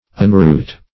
Meaning of unroot. unroot synonyms, pronunciation, spelling and more from Free Dictionary.
Search Result for " unroot" : The Collaborative International Dictionary of English v.0.48: Unroot \Un*root"\, v. t. [1st pref. un- + root.]
unroot.mp3